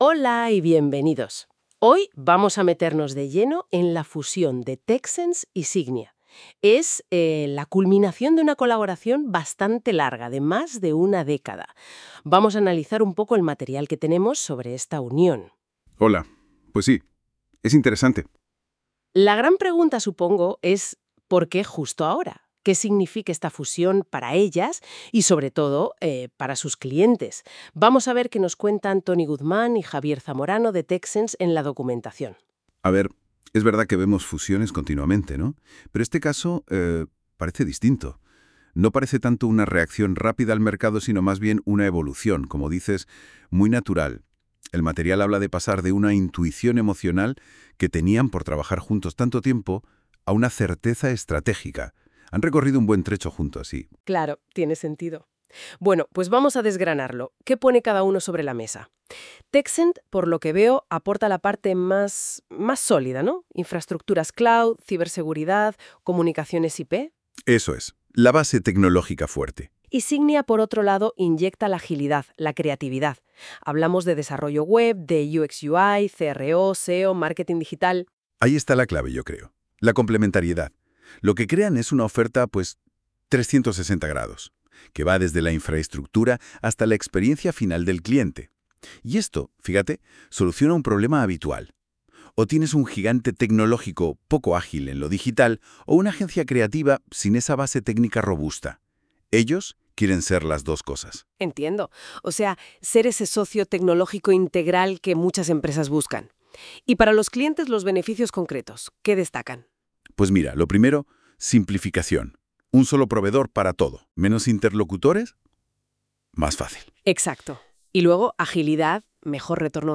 Si quieres, puedes escuchar un podcast sobre el artículo generado con IA.